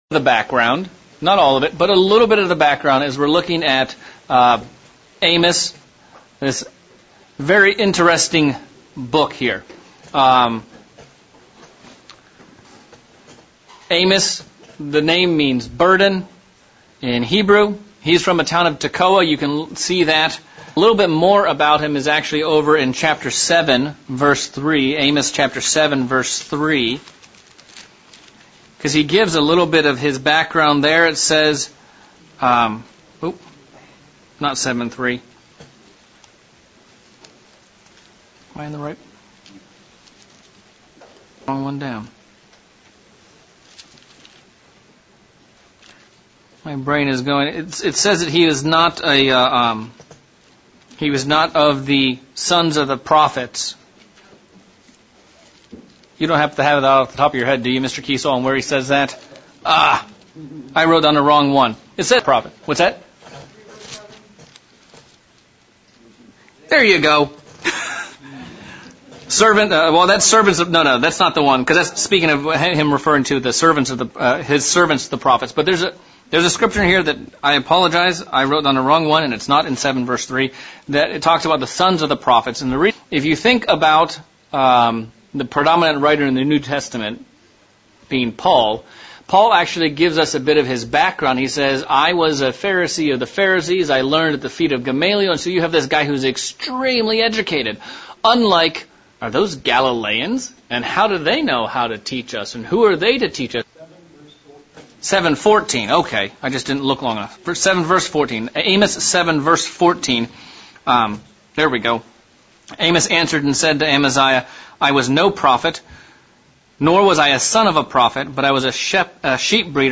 October 2023 Bible Study - Amos 2
Given in Central Illinois